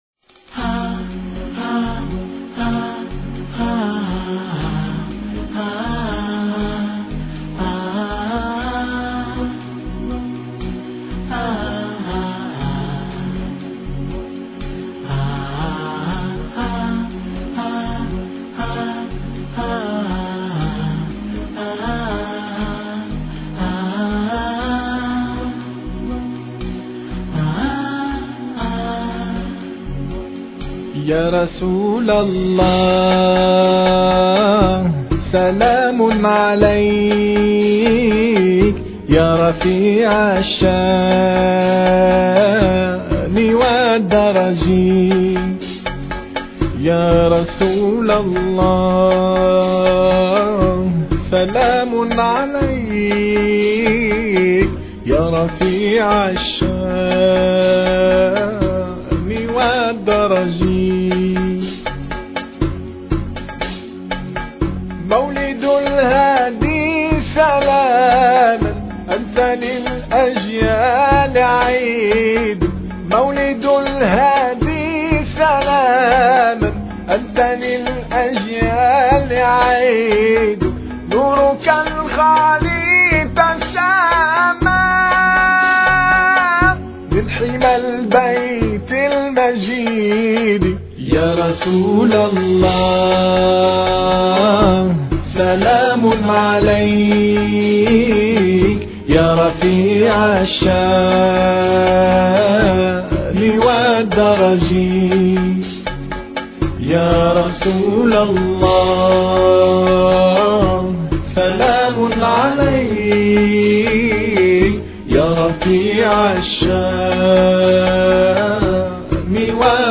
guidance orateur: quelques jeunes période de temps: 00:00:00